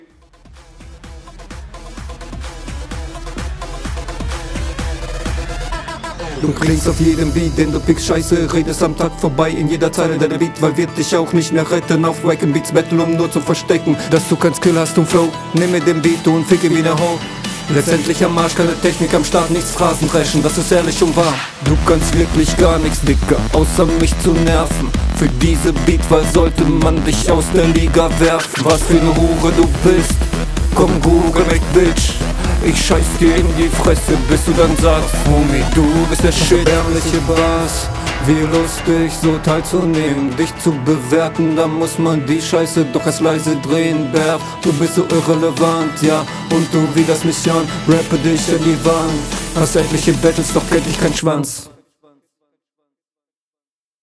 Der schnelle Flow am Anfang wars nicht, also es passt nicht zum Beat, aber der …
Dir liegt der Beat auch nicht so, aber klingt etwas cooler.